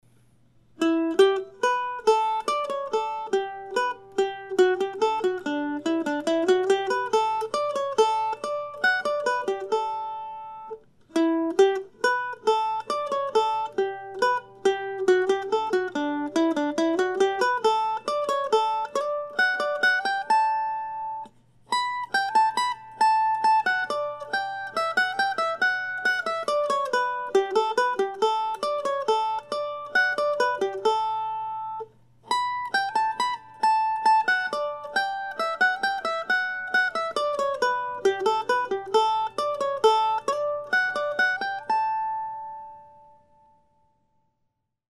As stated in my previous post, these are short pieces modeled after James Oswald's 18th century divertimentos for "guittar" and titled after some of my favorite places here in the Decorah area.
I'll be playing all ten of these Postcards tomorrow night at Java John's Coffee House, along with music by James Oswald and others, from 7:00-9:00 p.m. Drop by if you would like to hear some solo mandolin music.